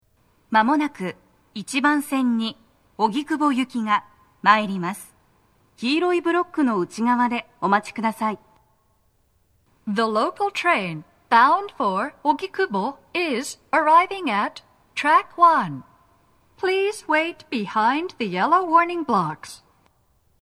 スピーカー種類 TOA天井型
🎤おススメ収録場所 1番線…最前部付近のスピーカー/2番線…最前部付近のスピーカー
鳴動は、やや遅めです。
１番線 荻窪方面 接近放送 【女声